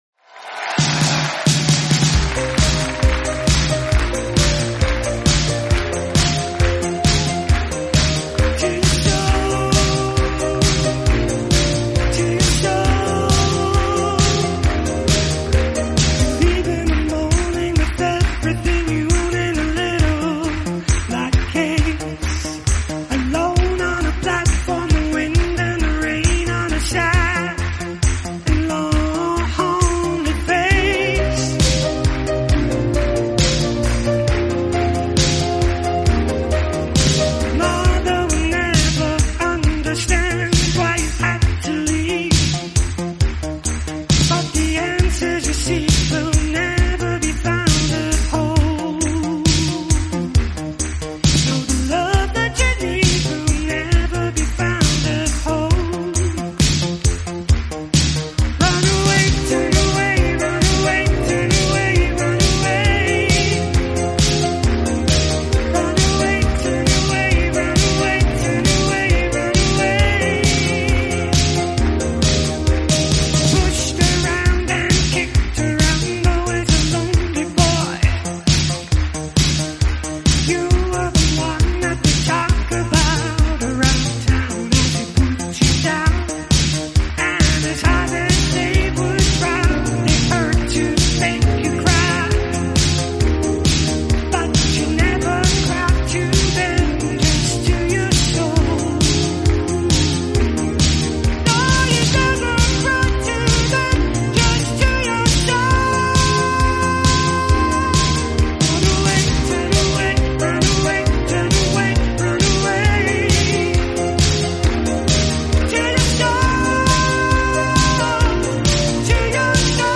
#80snewwave